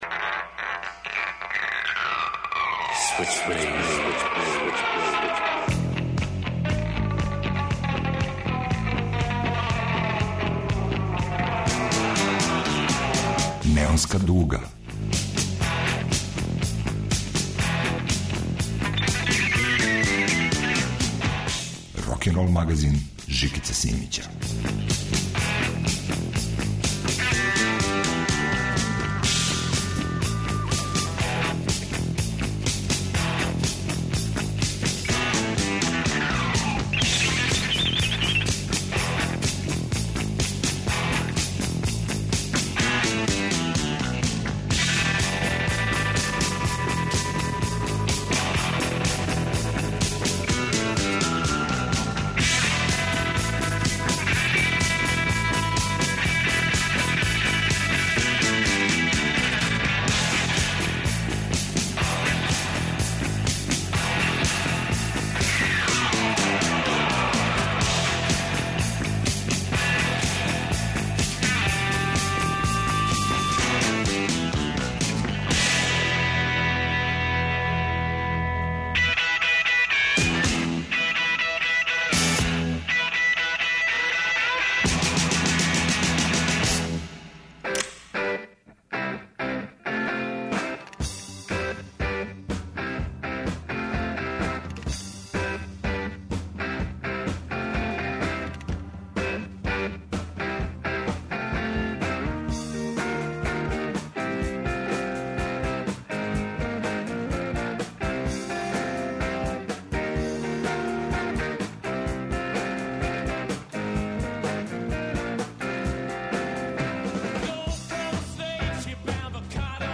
Кантри шеме, блуз акорди и беле линије.
Рокенрол као музички скор за живот на дивљој страни. Вратоломни сурф кроз време и жанрове.